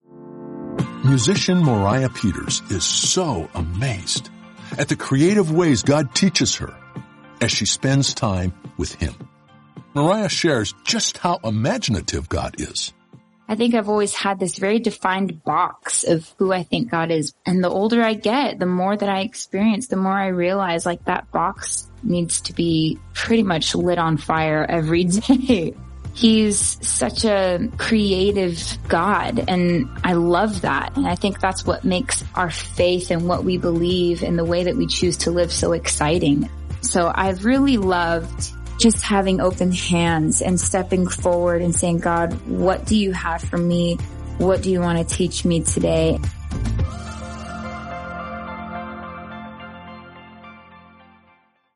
Singer Moriah Peters talks about God’s creativity.